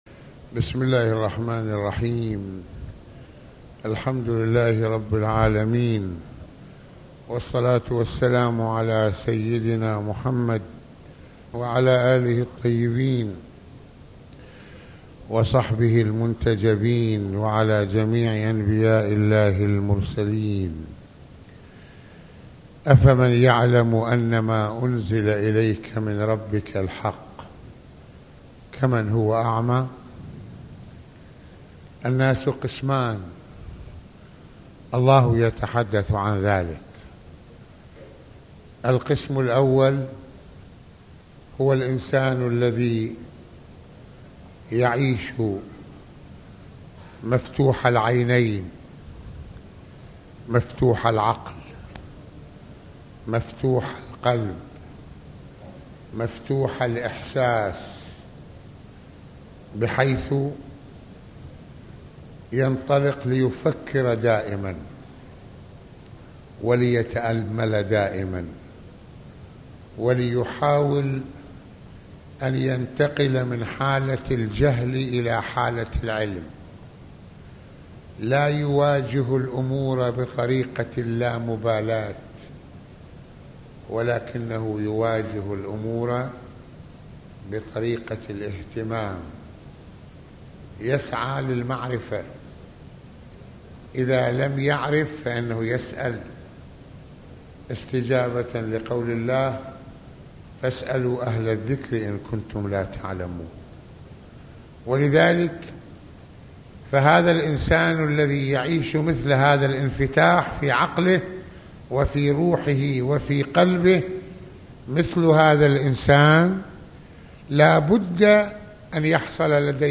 ملفات وروابط - المناسبة : موعظة ليلة الجمعة المكان : مسجد الإمامين الحسنين (ع) المدة : 37د | 21ث المواضيع : ماعمى البصر وليس البصيرة - الاسراع الى مغفرة من الله - العمل للوصول الى الجنة - ما هو التشيع ؟